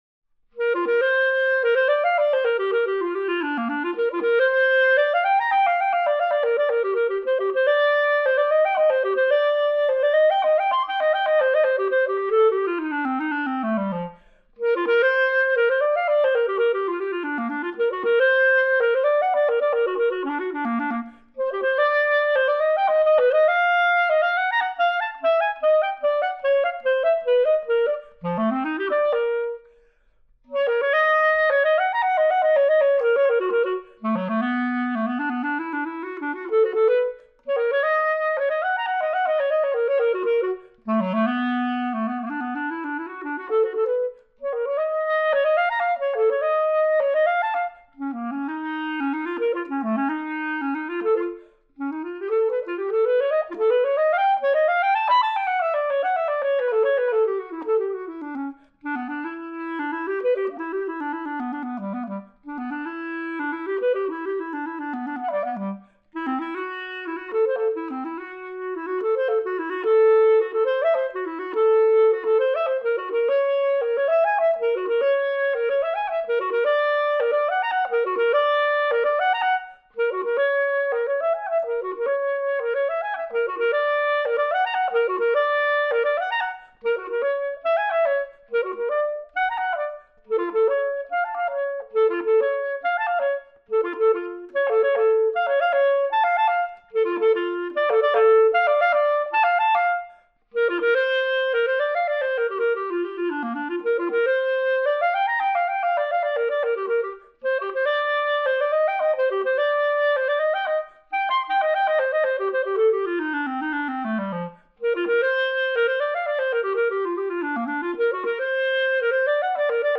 Joseph Horovitz: Sonatina for clarinet and piano (1981)